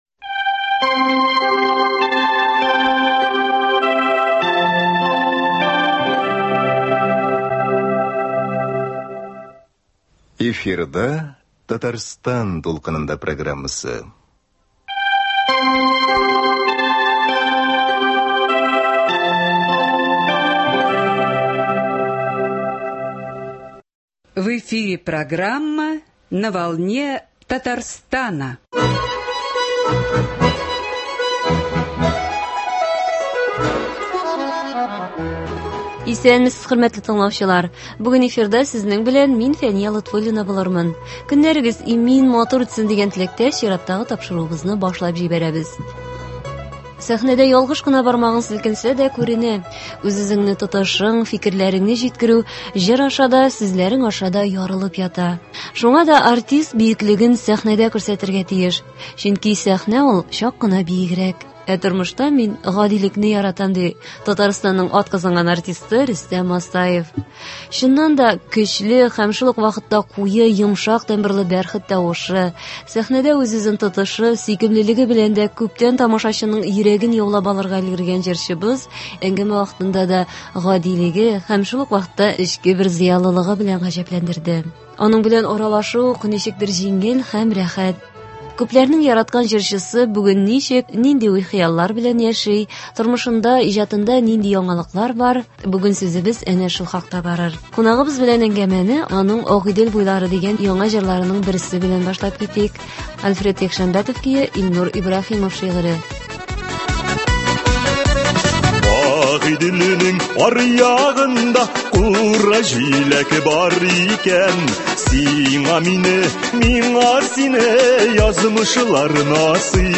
Чыннан да, көчле һәм шул ук вакытта куе йомшак тембрлы бәрхет тавышы, сәхнәдә үз-үзен тотышы, сөйкемлелеге белән дә күптән тамашачының йөрәген яулап өлгергән җырчыбыз әңгәмә вакытында да гадилеге, әмма шул ук вакытта эчке бер зыялылыгы белән дә гаҗәпләндерде.